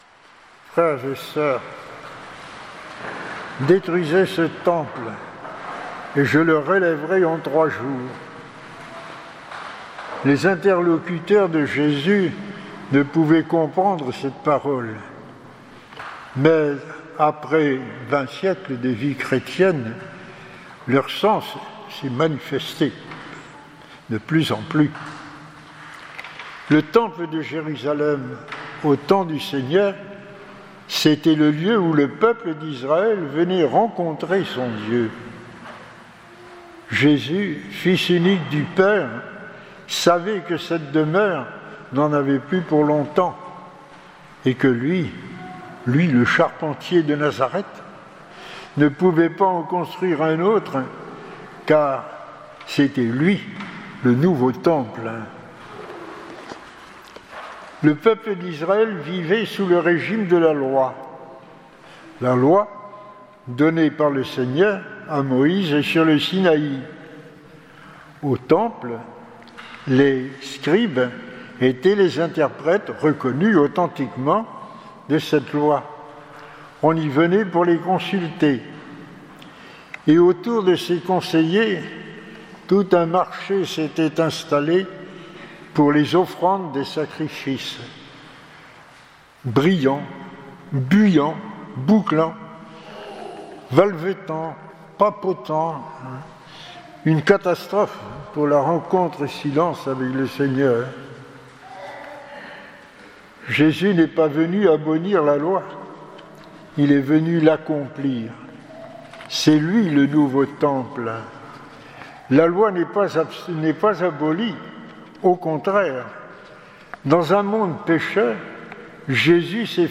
Homélie du 3ème dimanche de Carême